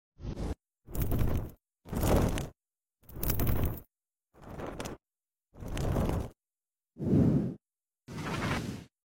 Transition! All the files is sound effects free download